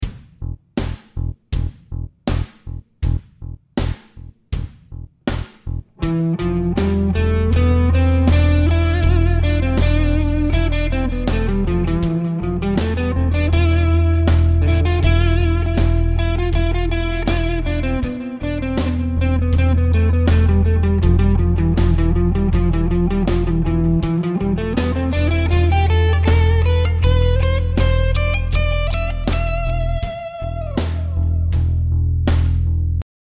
The bass is just playing the E note in all examples.
Phrygian
Phrygian has an even darker sound to it.